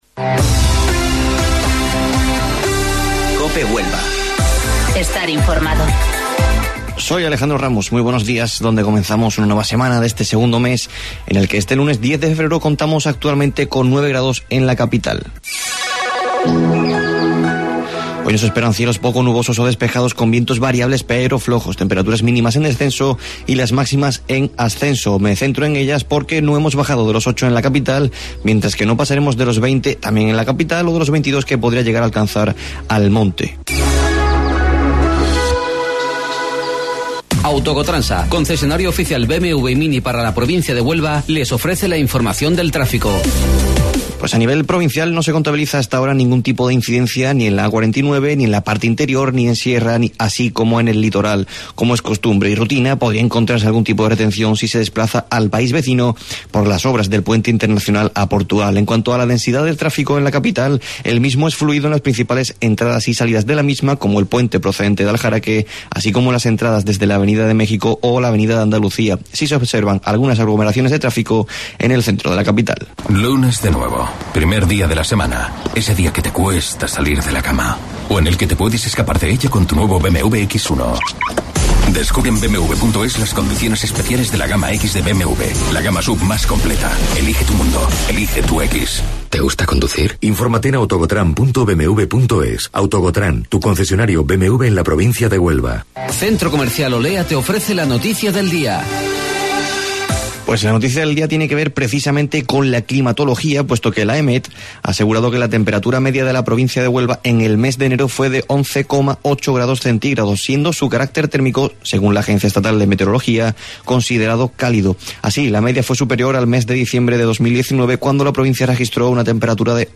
AUDIO: Informativo Local 08:25 del 10 de Febrero